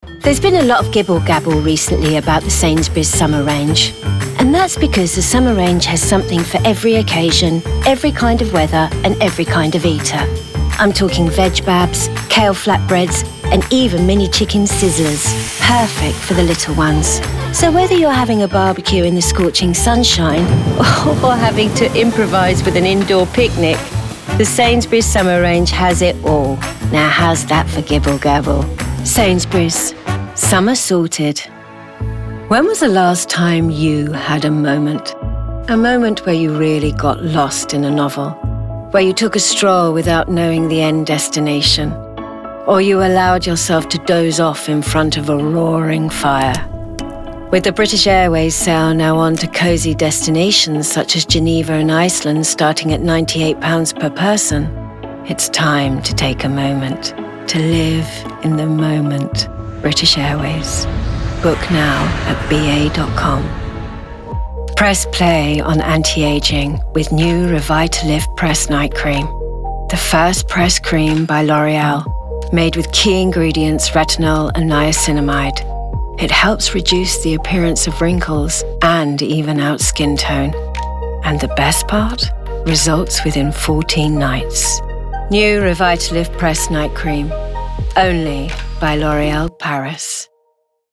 Voice Reel
Commercial Montage